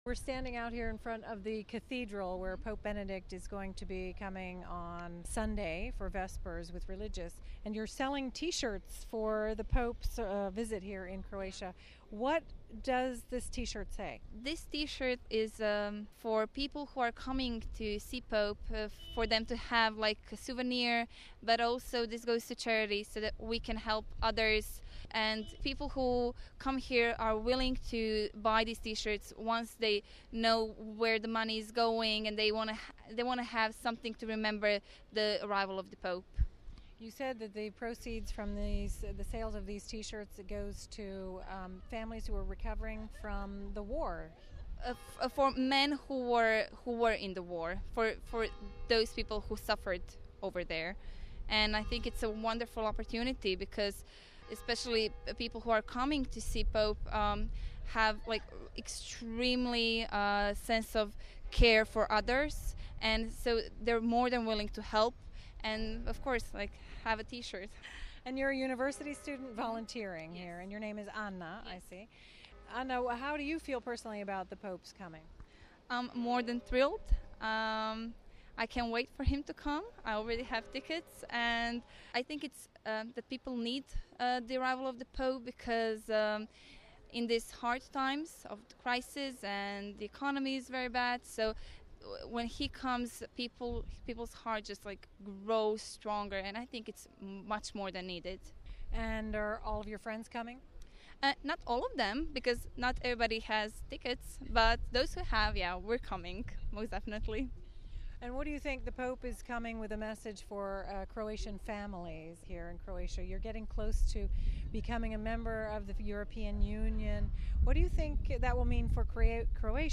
As the people of Croatia prepare to welcome Pope Benedict to the capital, Zagreb, on Saturday, thousands of volunteers are on hand to help ensure the smooth running of the two day visit.